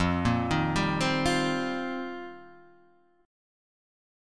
F 9th Suspended 4th